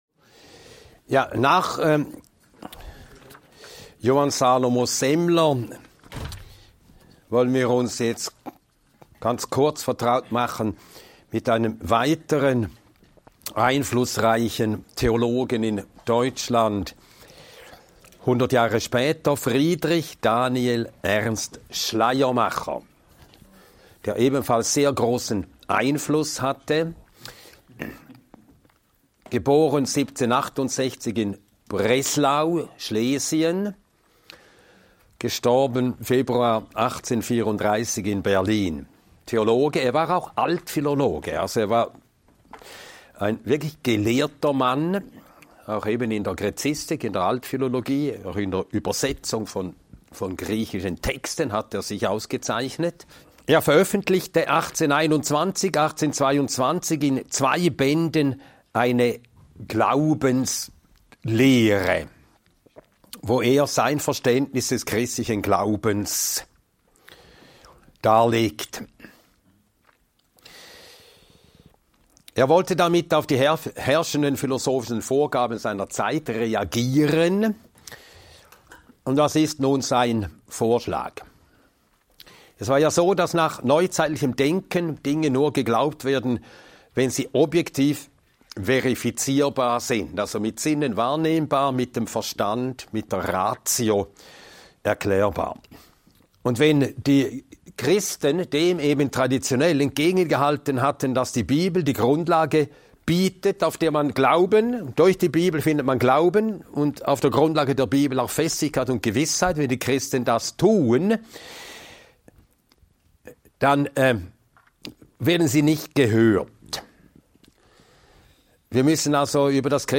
Dieser Vortrag beleuchtet den folgenschweren Wandel im Schriftverständnis des 19. und 20. Jahrhunderts anhand der prägenden Theologen Schleiermacher und Troeltsch.